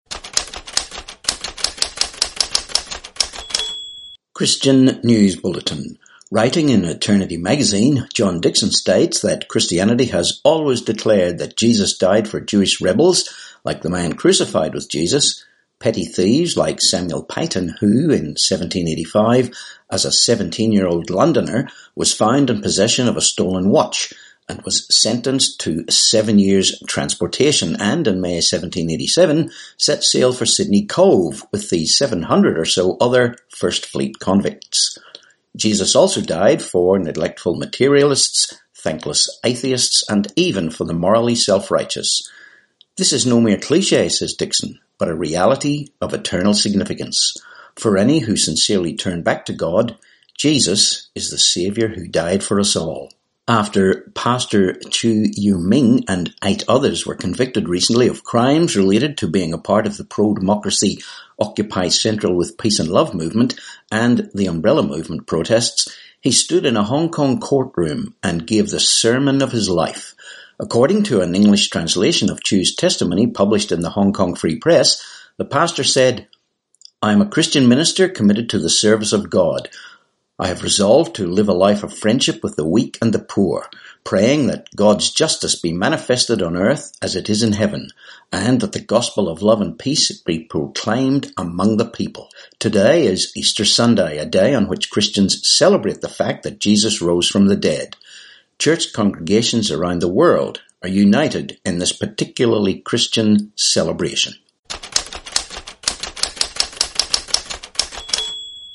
21Apr19 Christian News Bulletin